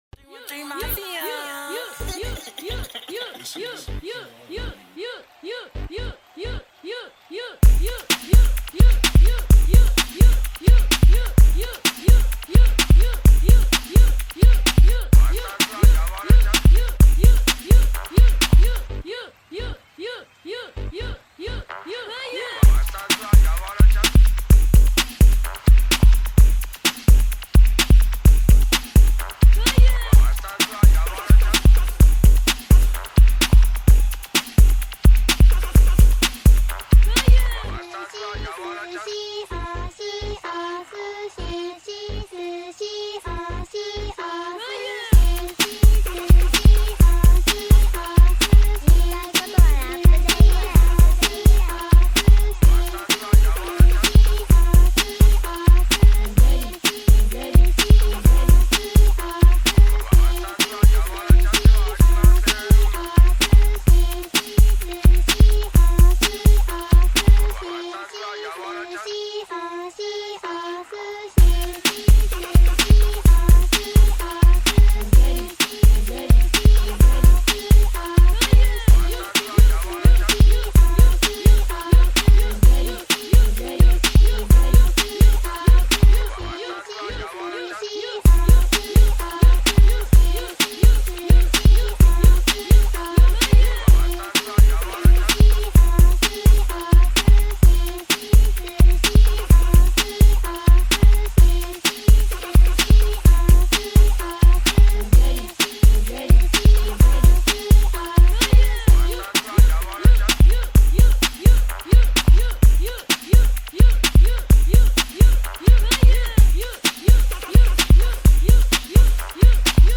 Here's the official instrumental
Rap Instrumentals